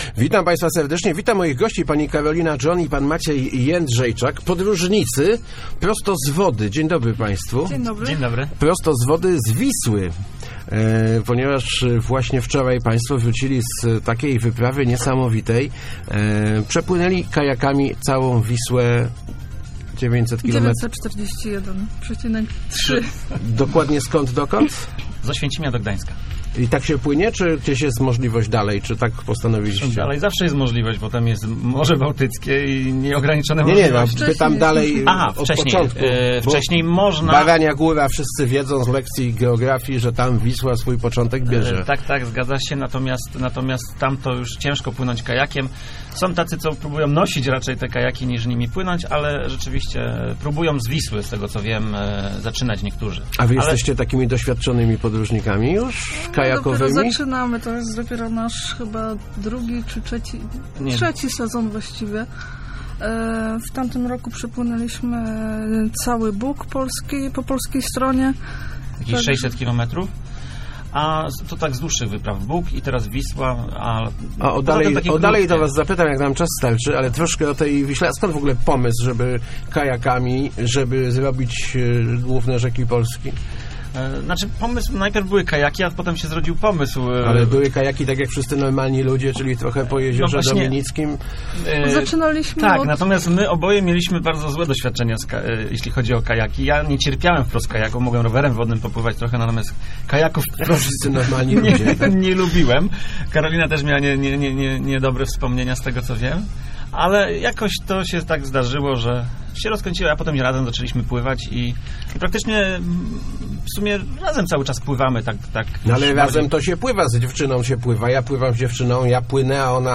W Rozmowach Elki wspominali t� przygod�.